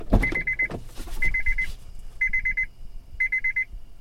Gun Silencer
The sound of a gun silencer being fired at someone.